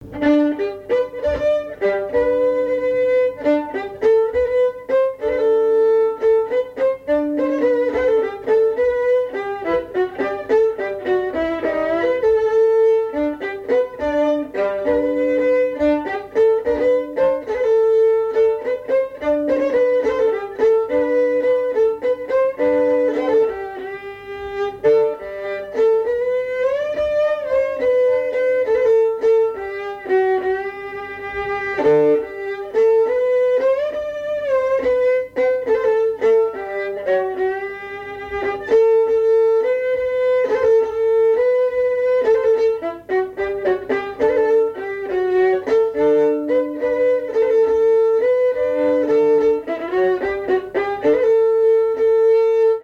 Valse - Sous les ponts de Paris
danse : valse
Pièce musicale inédite